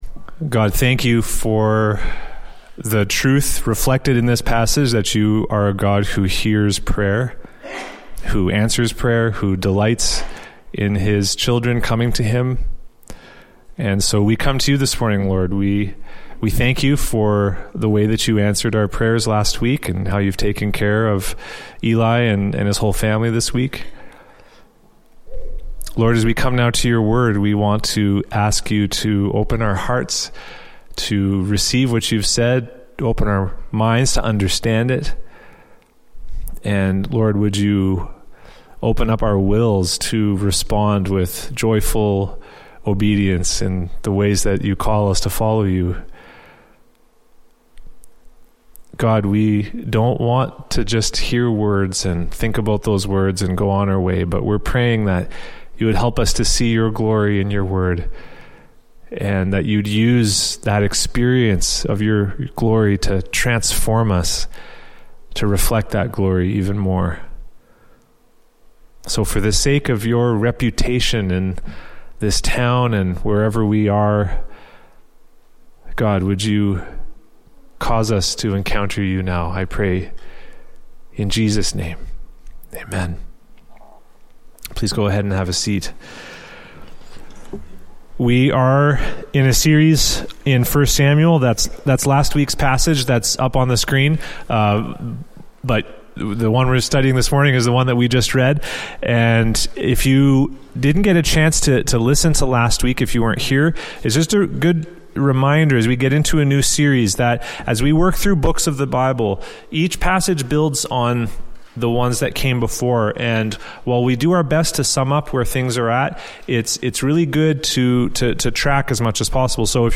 Emmanuel Baptist Church of Nipawin Sermons